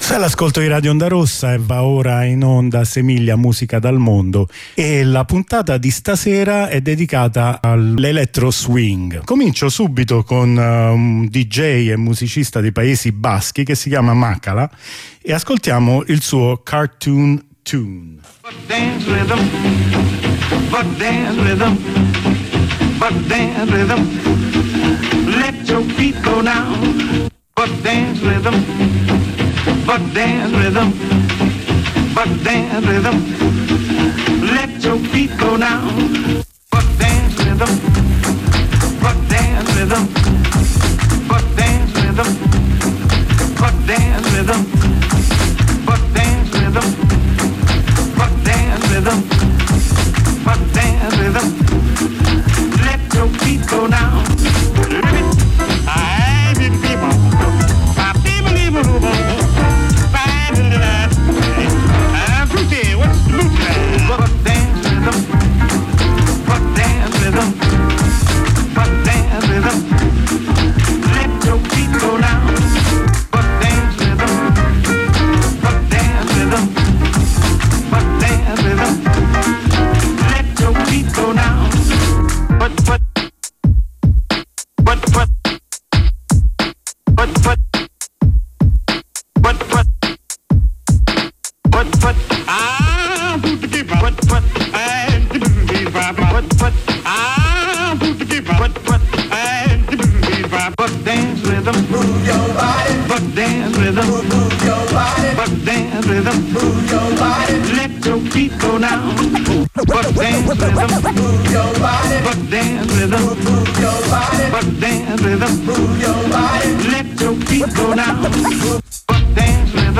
musica elettronica | Radio Onda Rossa